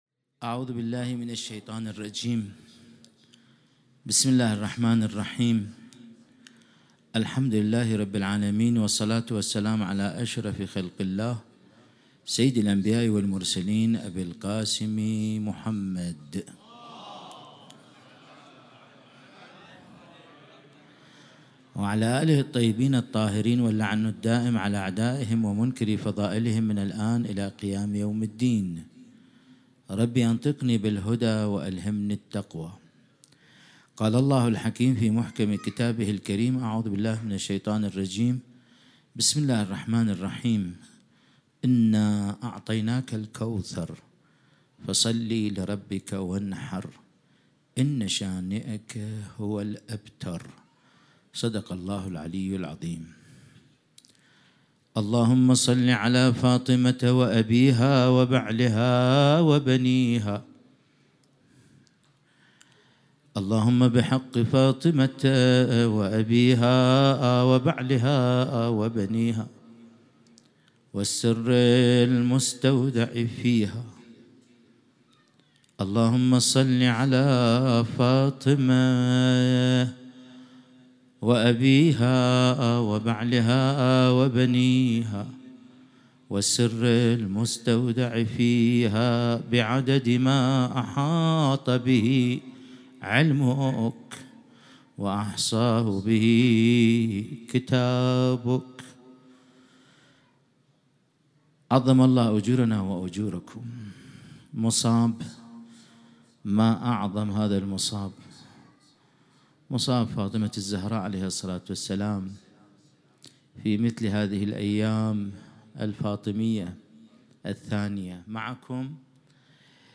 سخنرانی عربی